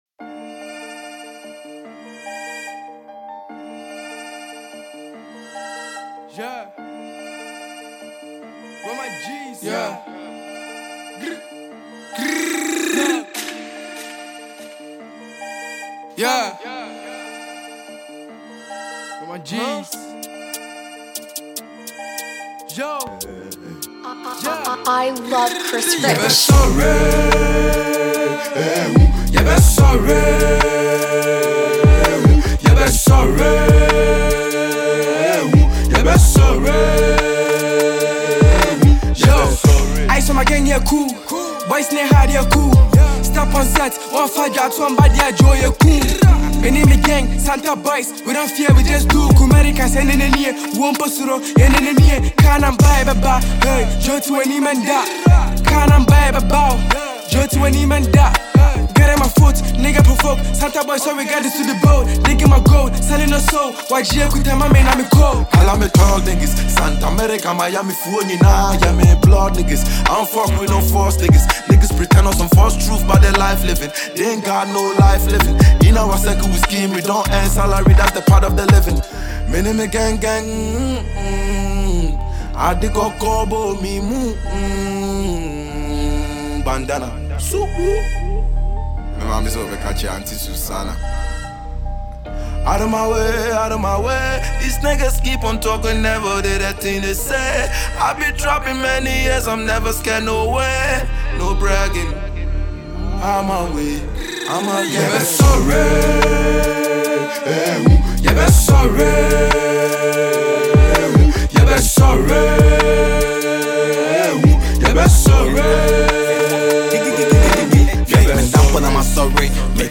Ghana Music
drill